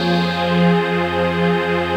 DM PAD2-47.wav